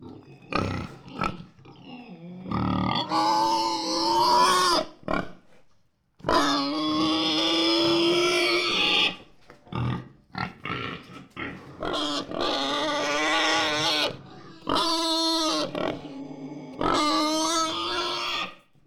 Домашние животные звуки скачать, слушать онлайн ✔в хорошем качестве